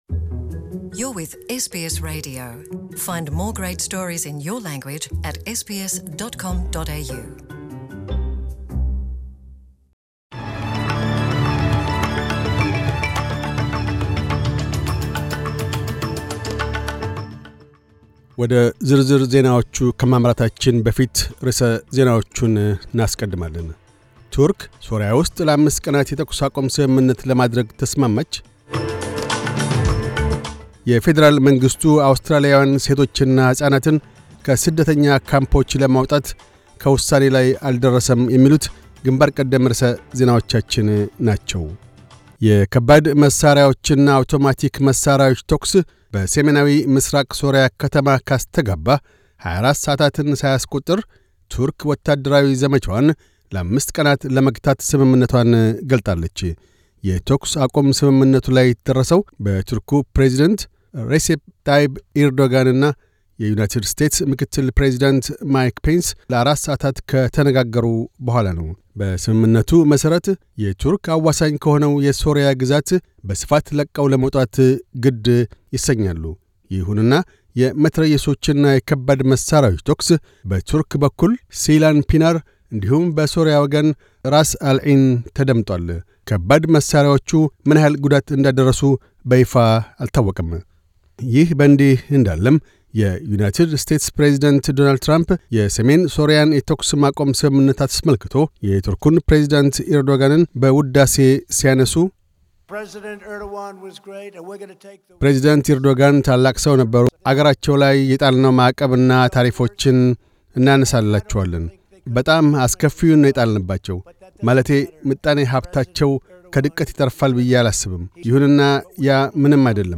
News Bulletin 1018